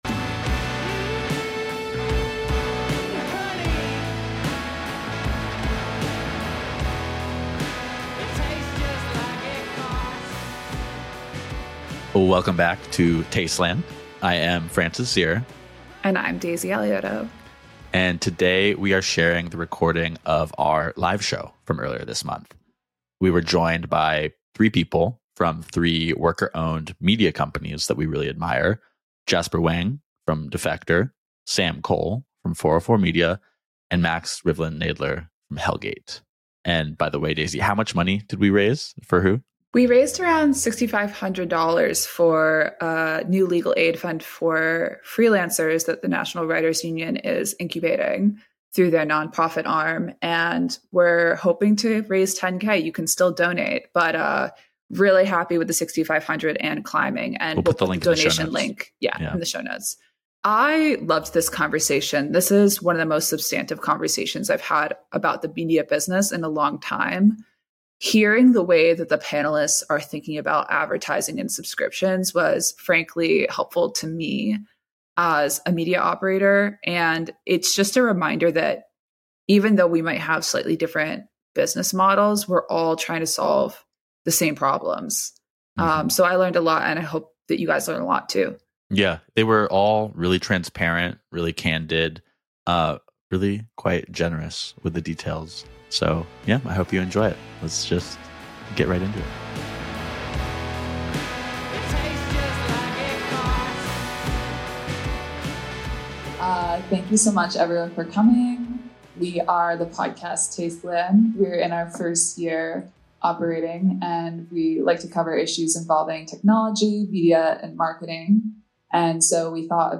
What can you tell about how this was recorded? This is from our live pod earlier this month in support of the National Writers Union's legal fund for media workers.